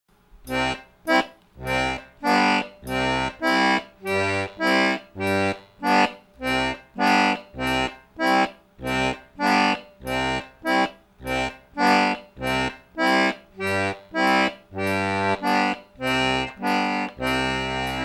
then add the left hand rhythm.
Wafus_l_hand.wma